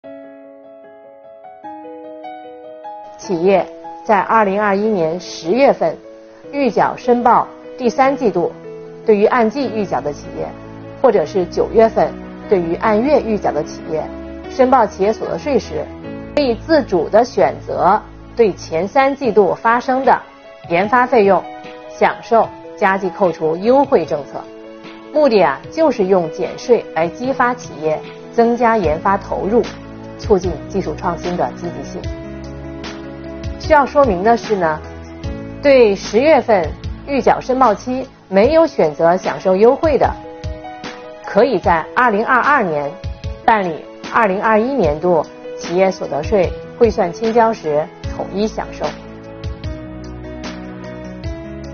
近日，国家税务总局推出最新一期“税务讲堂”网上公开课，税务总局所得税司副司长阳民详细解读研发费用加计扣除政策的有关背景、内容和办理方式等内容，帮助纳税人缴费人更好了解政策、适用政策。